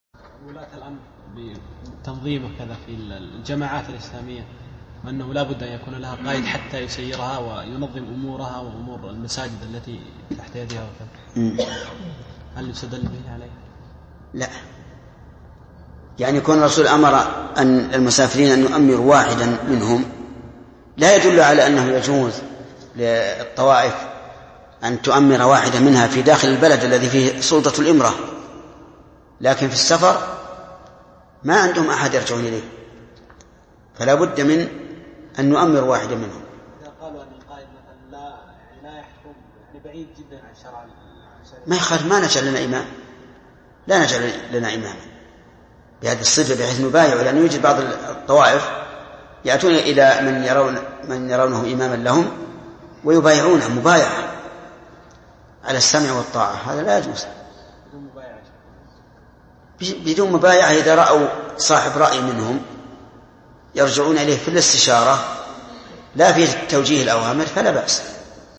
Download audio file Downloaded: 814 Played: 385 Artist: الشيخ ابن عثيمين Title: حكم بيعة الأمراء في الجماعات السياسية والتنظيمات الدعوية Album: موقع النهج الواضح Length: 1:09 minutes (346.13 KB) Format: MP3 Mono 22kHz 32Kbps (VBR)